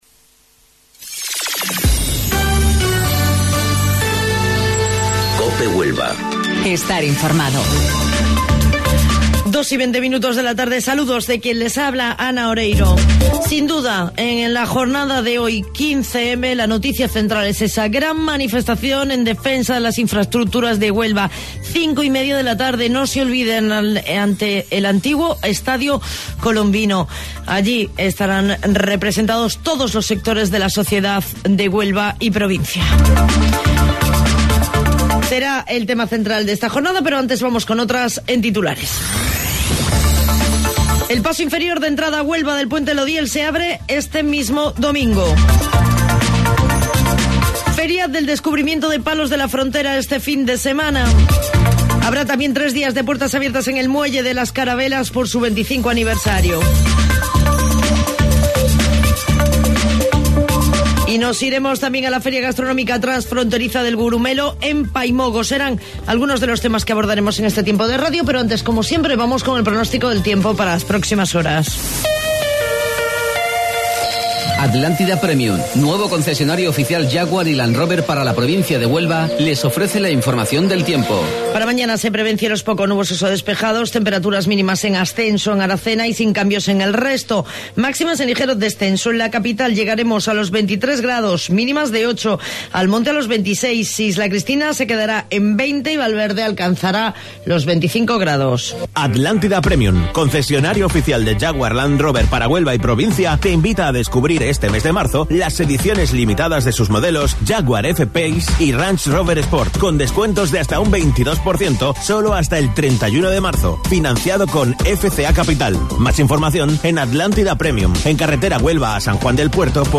AUDIO: Informativo Local 14:20 del 15 de Marzo